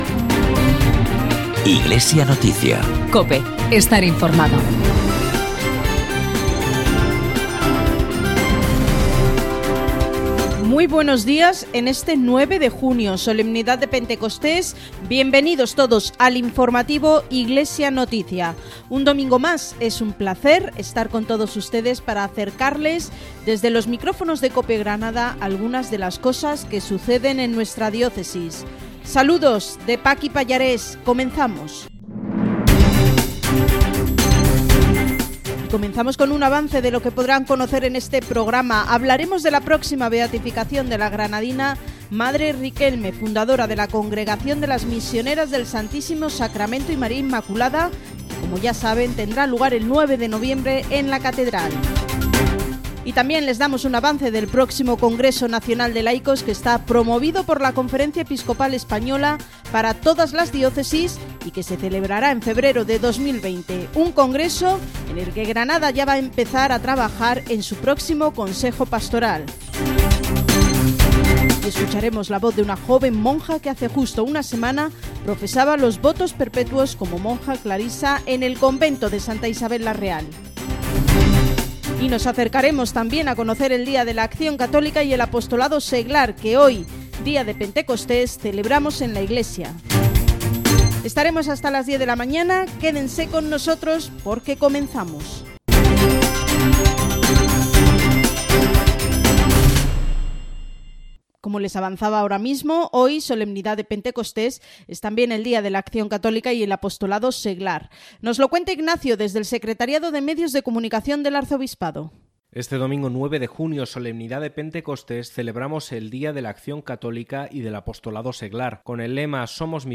Informativo emitido en COPE Granada el 9 de junio, Solemnidad de Pentecostés.
También hablamos de la próxima beatificación en Granada de Madre Riquelme y escucharemos al Papa Francisco y su invitación de intención para orar este mes.